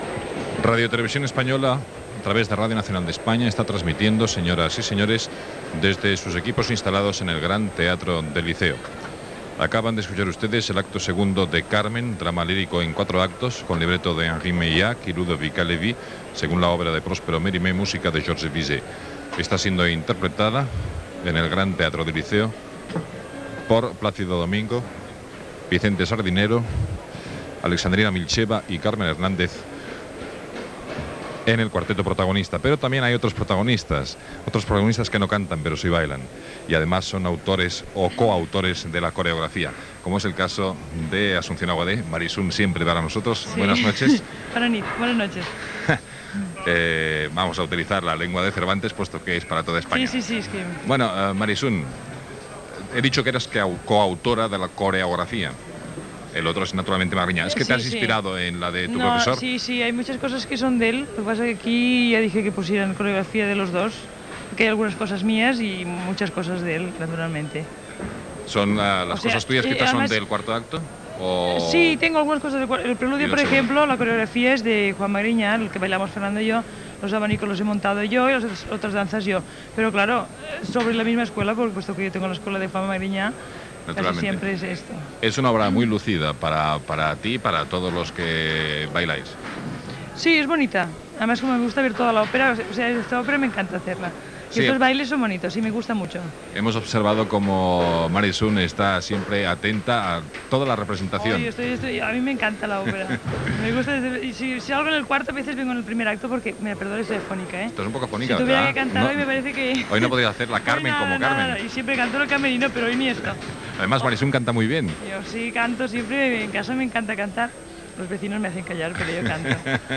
Transmissió de l'òpera "Carmen" de Georges Bizet des del Gran Teatre del Liceu de Barcelona.
Musical